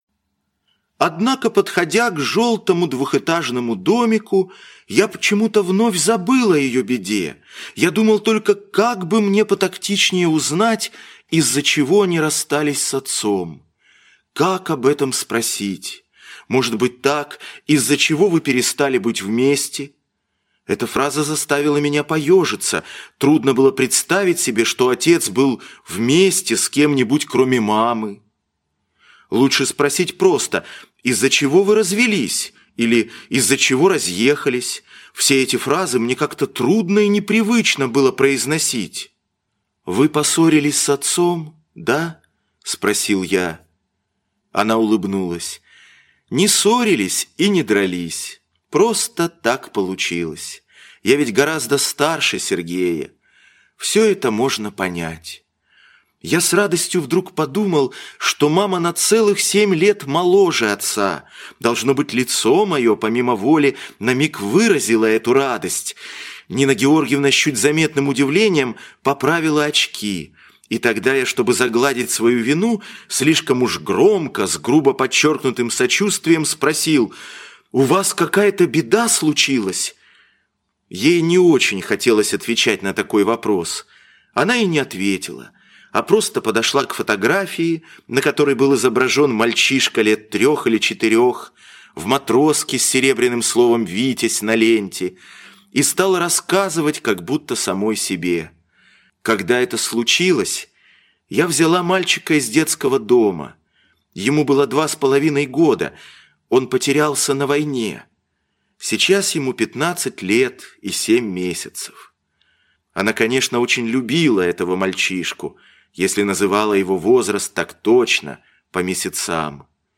А тем временем где-то - аудио повесть Алексина - слушать